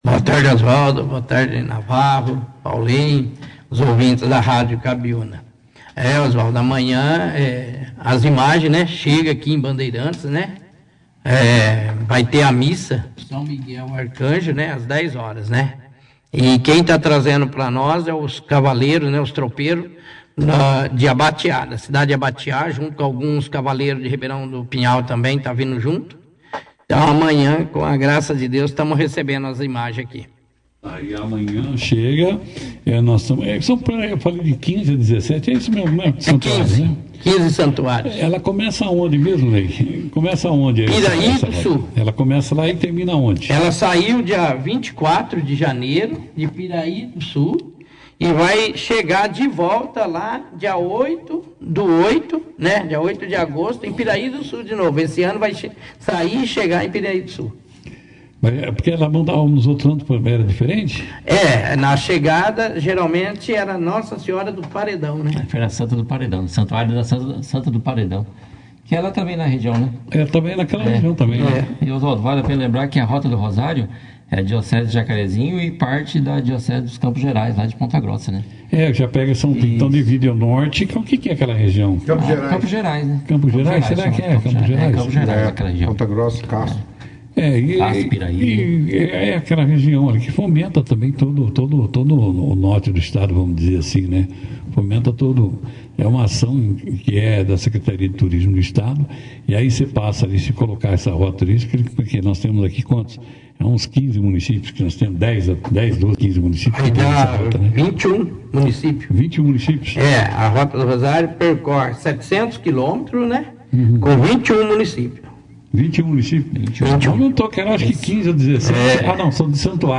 Durante a entrevista, os tropeiros destacaram o significado espiritual da caminhada e as experiências vividas ao longo do percurso.